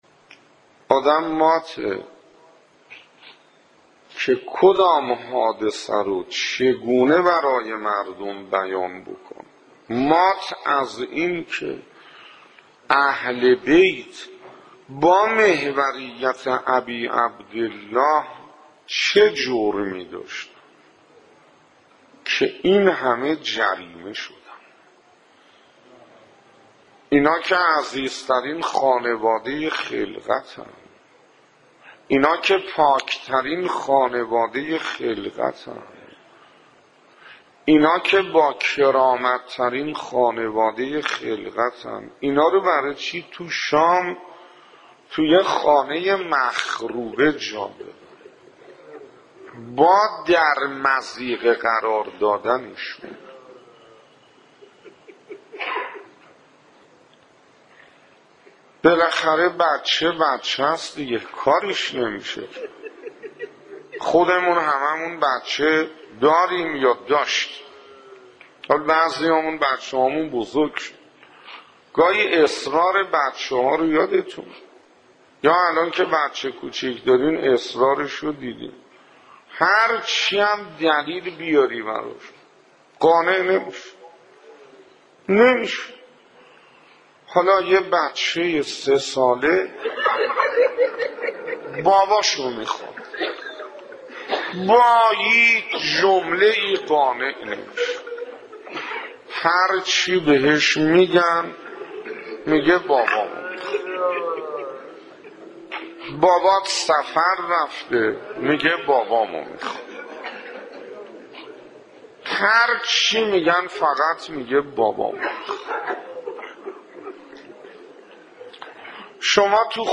روضه حضرت رقیه - 2
روضه حضرت رقیه - 2 خطیب: استاد حسين انصاريان مدت زمان: 00:04:32